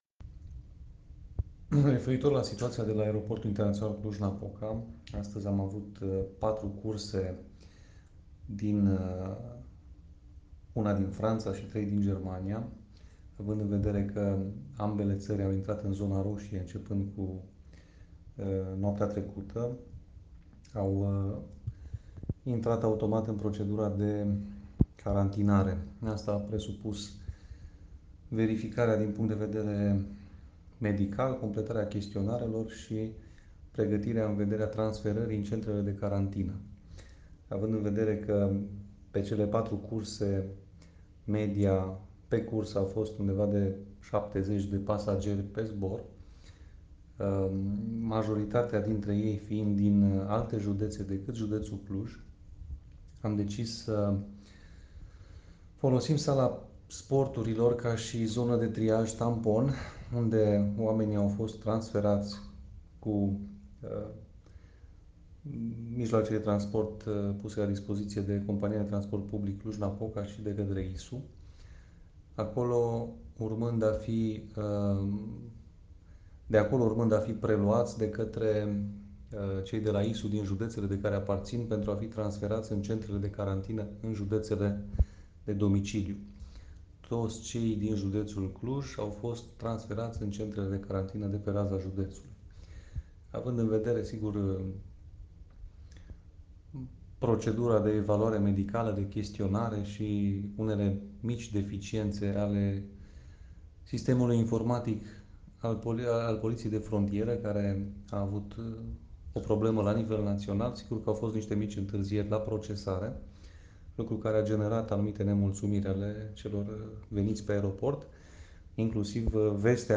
Explicaţia completă a prefectului, în mesajul audio ataşat.